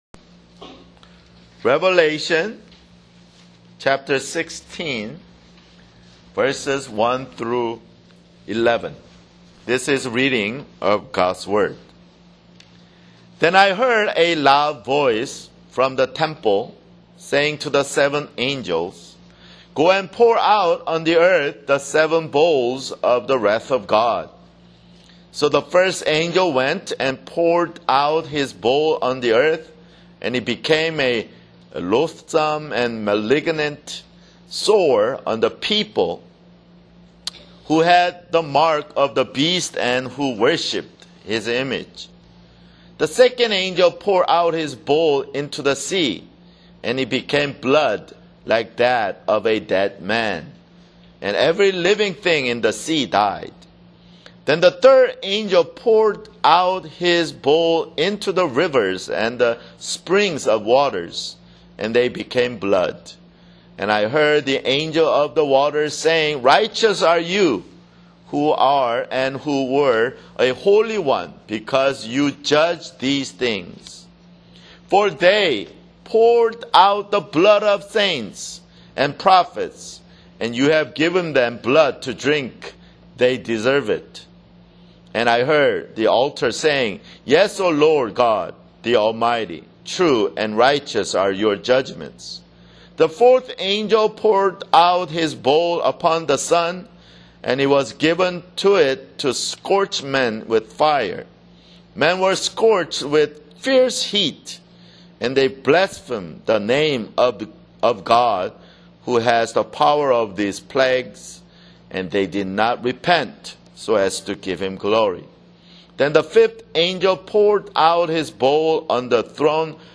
[Sermon] Revelation (60)